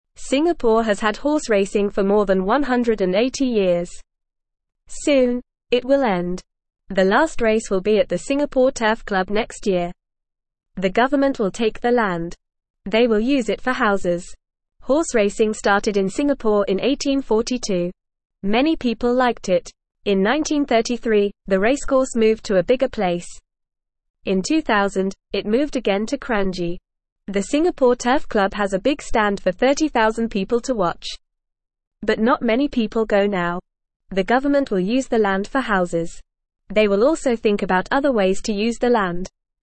Fast
English-Newsroom-Beginner-FAST-Reading-Singapore-Horse-Racing-Ends-Soon.mp3